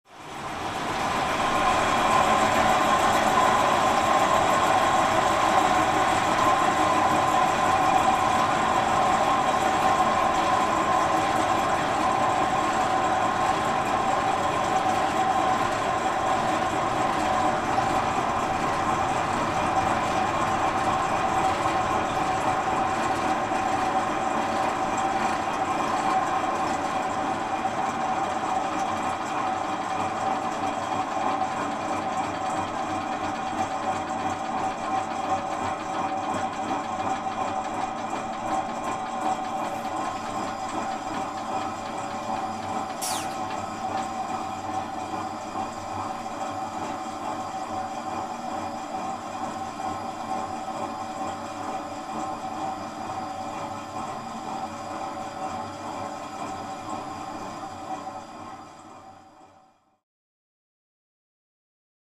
Farm Equipment | Sneak On The Lot
Interior Point of View, Combine / Tractor Running, Low Rumble.